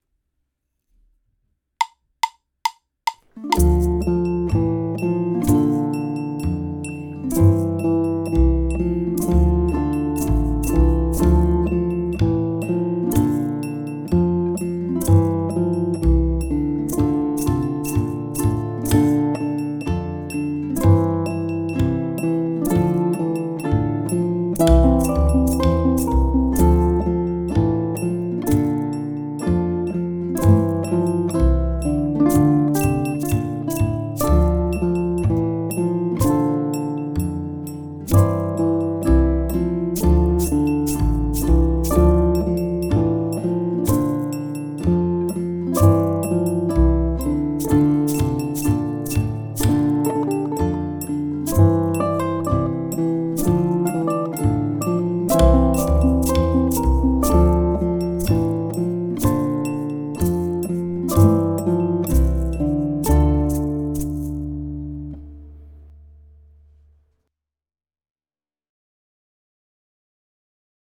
First and Only | Melody and chords
First_and_Only_GTR_mix.mp3